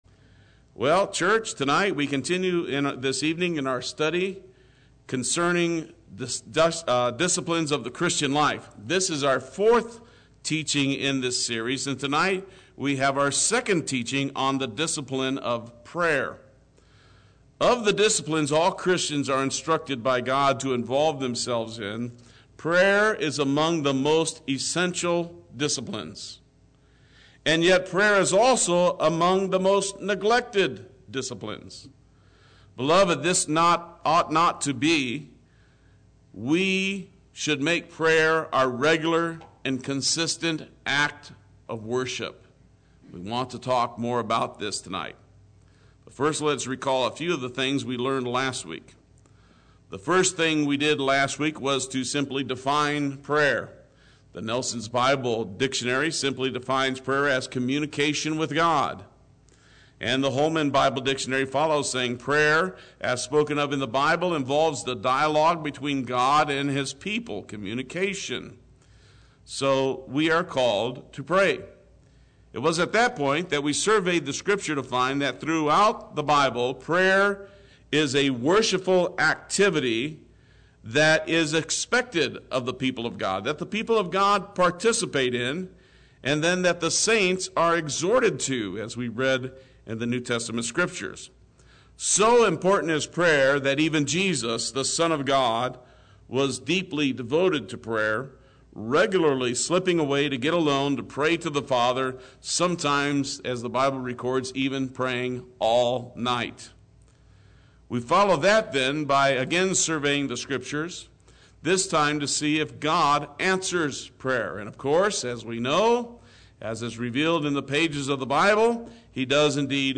Play Sermon Get HCF Teaching Automatically.
Part 2 Wednesday Worship